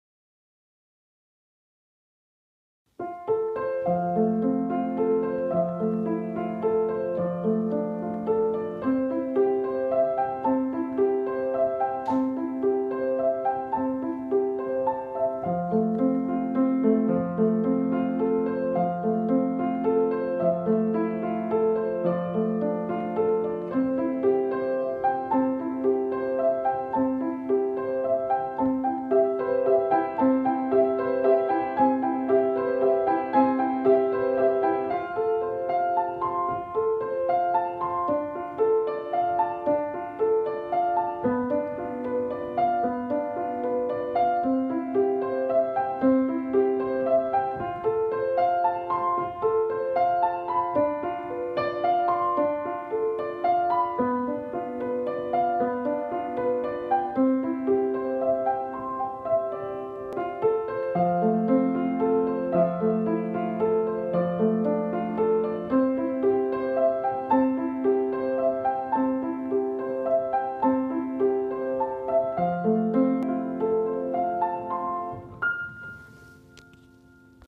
There is not a great deal to say about it, as it is just a fairly superficial little musical sketch.
sunlight-on-the-river-piano.mp3